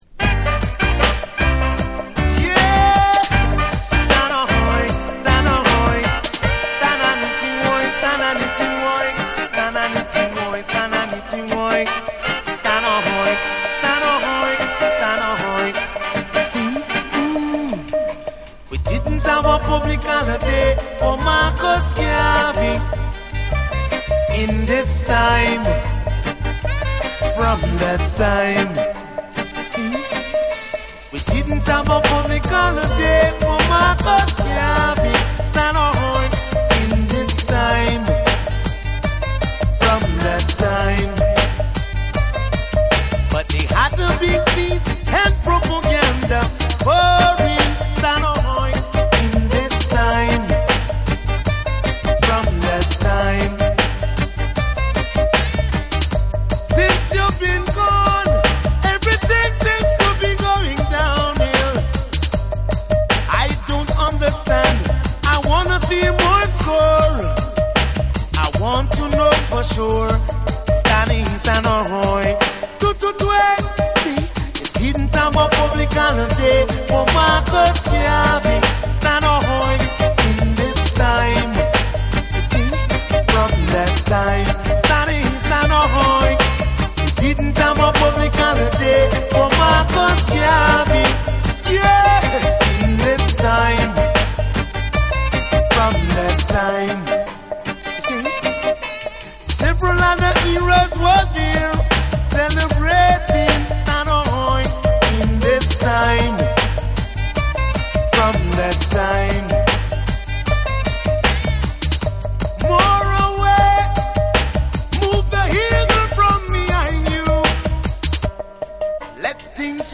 タ重量級リズムを背景に